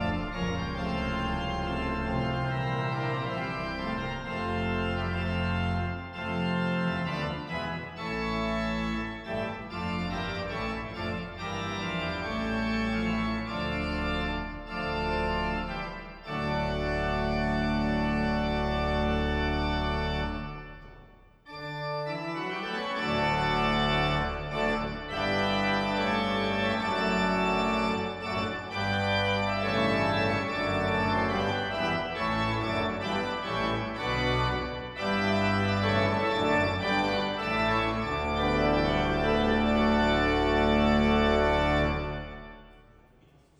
Instrumente Ladach Pfeifenorgeln, Orgelteile und Klaviere